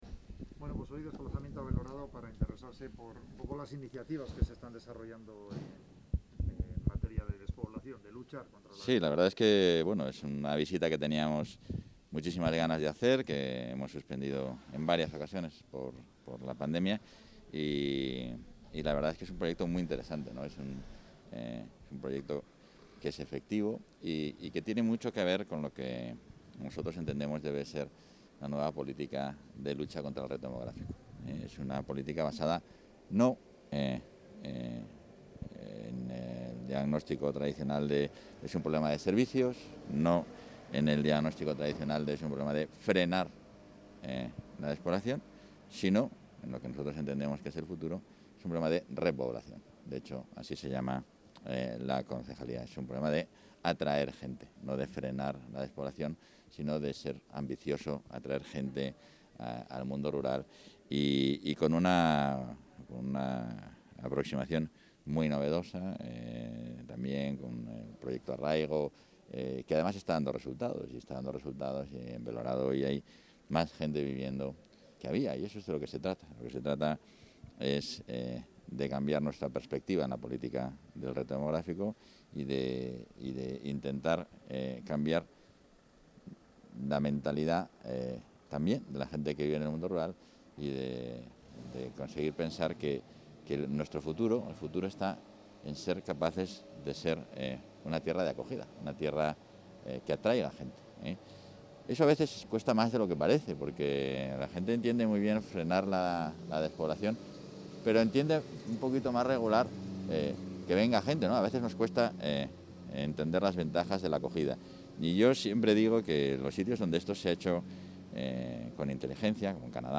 Declaraciones del vicepresidente y portavoz.